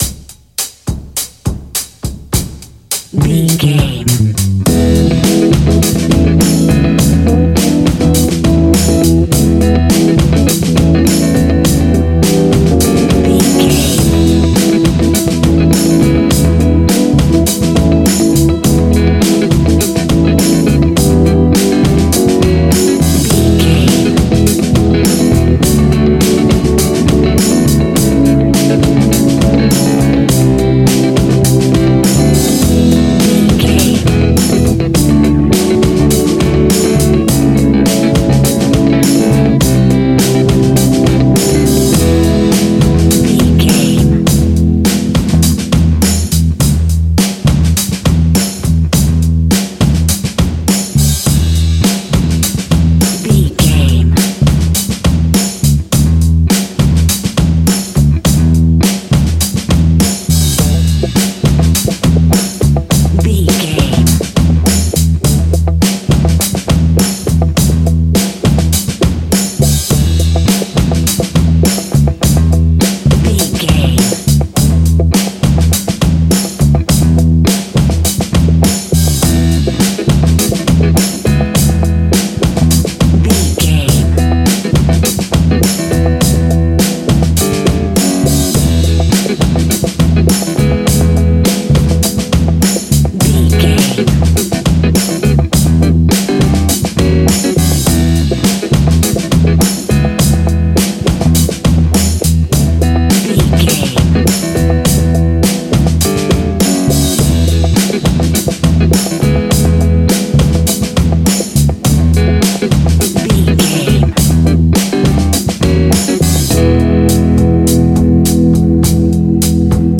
Aeolian/Minor
lively
electric guitar
electric organ
bass guitar
saxophone
percussion